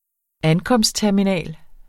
Udtale [ ˈankʌmsd- ]